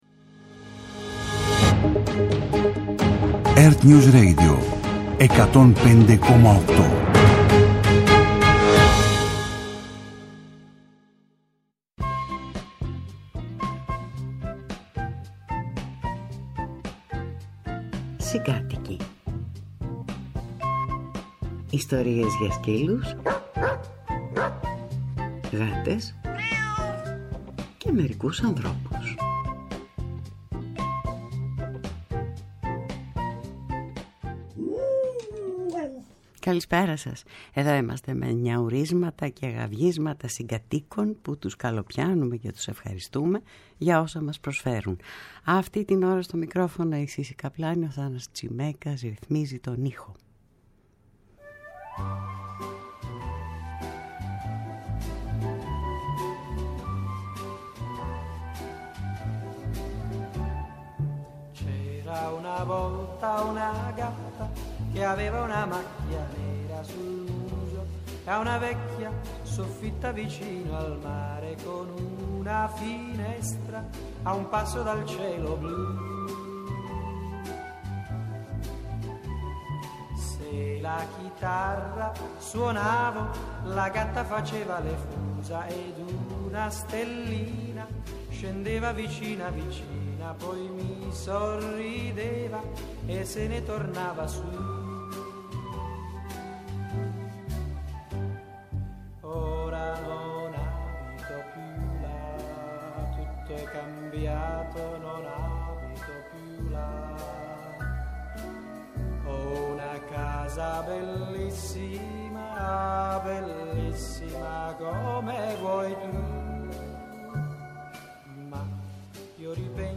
Μια ραδιοφωνική εκπομπή που όλοι οι καλοί χωράνε.